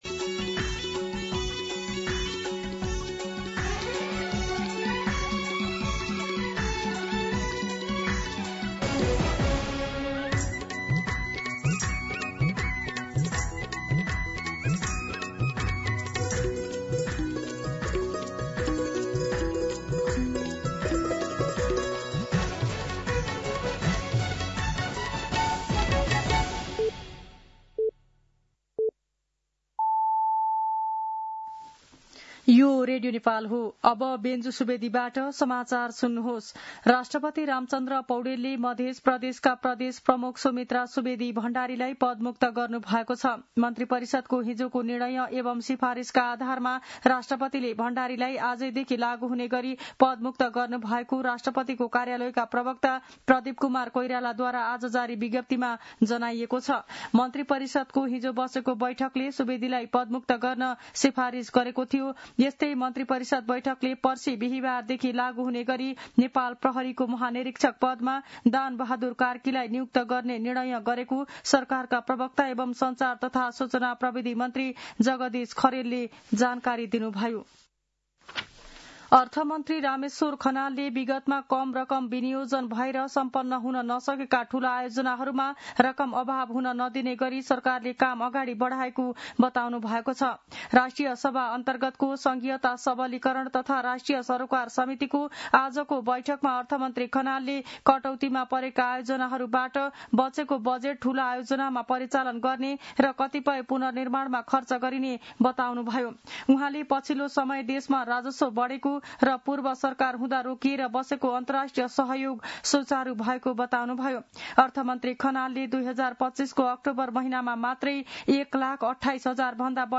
दिउँसो १ बजेको नेपाली समाचार : २५ कार्तिक , २०८२